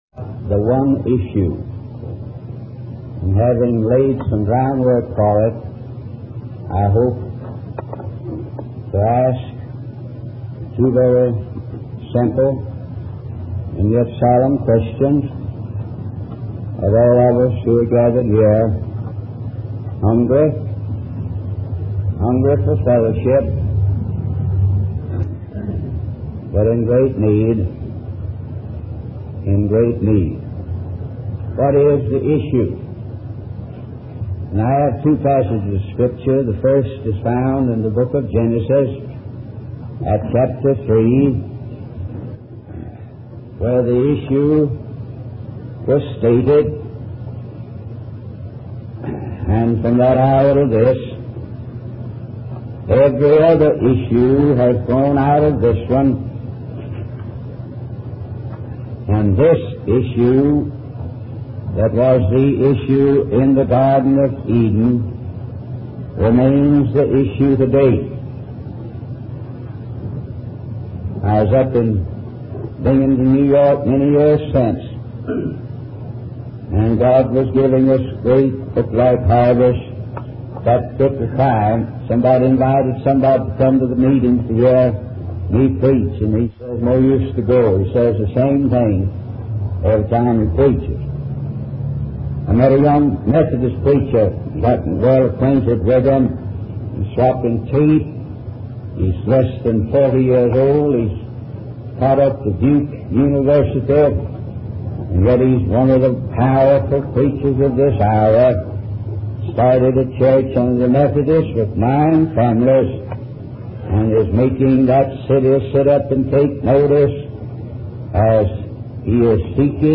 In this sermon, the preacher addresses the issue of people attending church on Sunday morning but not getting involved in the world around them. He compares this attitude to the people in New York City who are focused on their own lives and not paying attention to the problems happening around them.